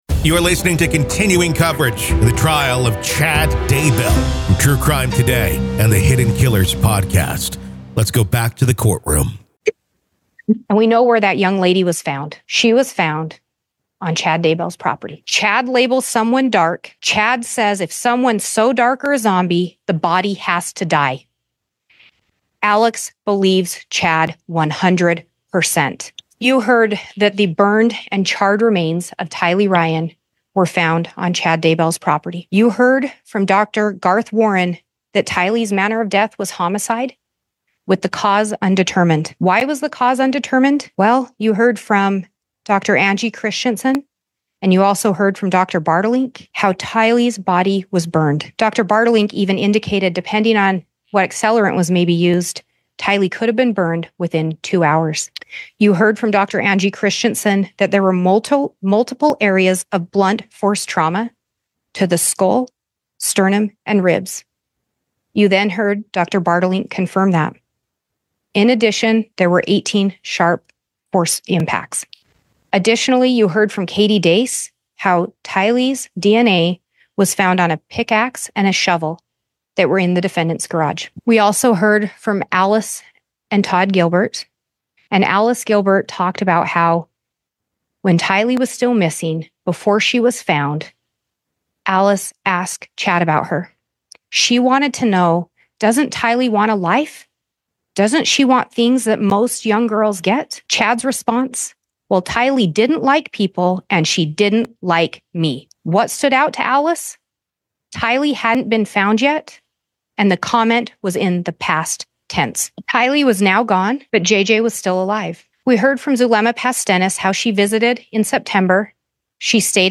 State Closing Arguments ID v Chad Daybell, Doomsday Prophet Murder Trial PART 2